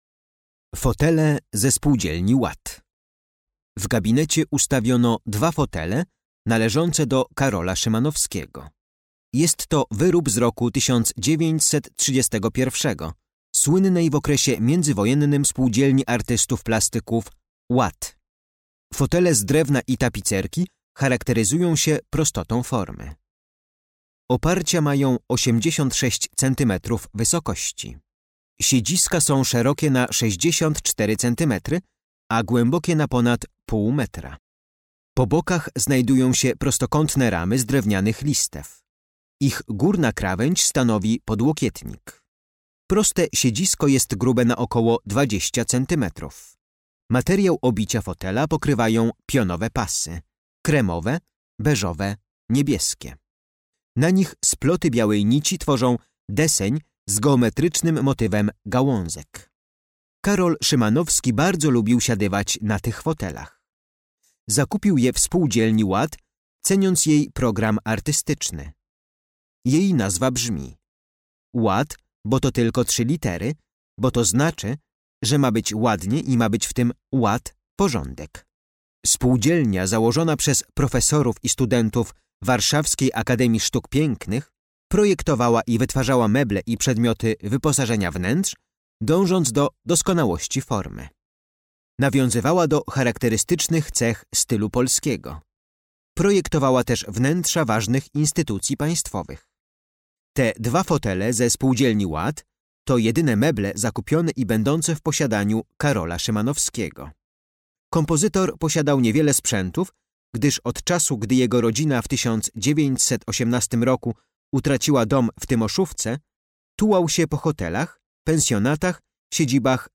Audiodeskrypcja dla wybranych eksponatów z kolekcji MNK znajdujących się w Muzeum Karola Szymanowskiego w willi "Atma" w Zakopanem.